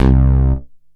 SYNTH BASS-1 0014.wav